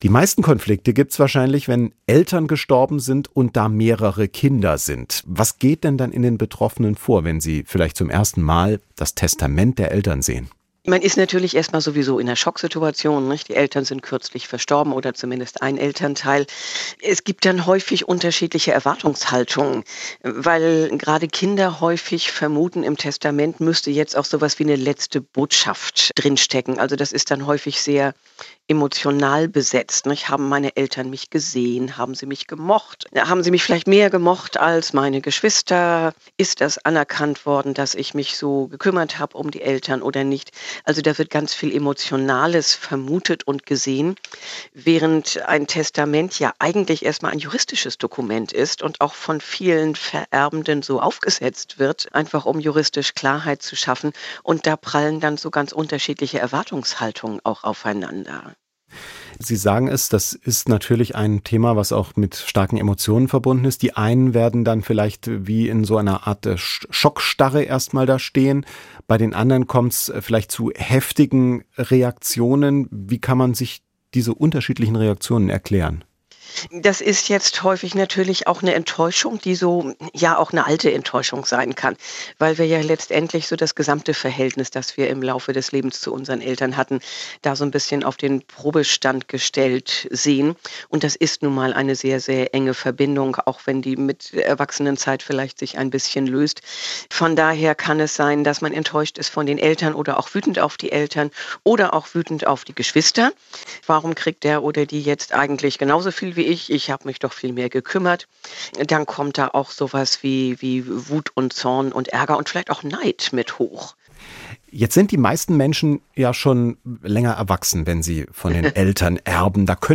Eine Pyschologin sagt, wie man dies verhindern kann.